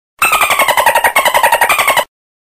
Nhạc Chuông DOLPHIN